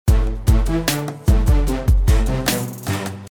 1-сырец исходник 2-Glue 3-Tan 4-SSL Comp (Waves) 5-Quad Compressor (SSL SL4000E)